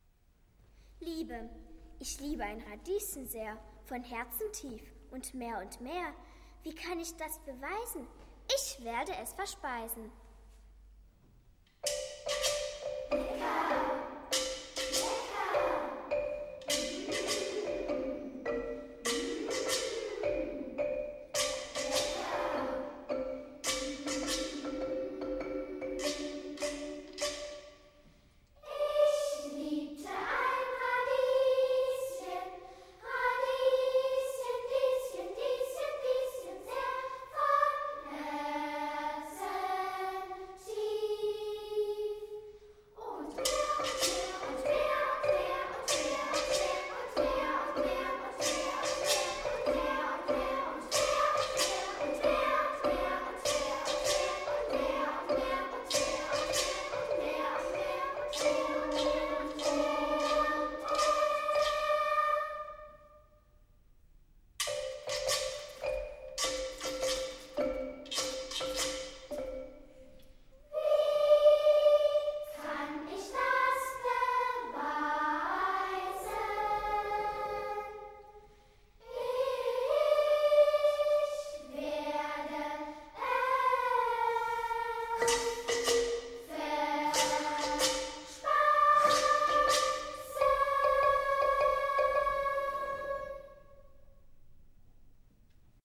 für  Kinderchor, 1998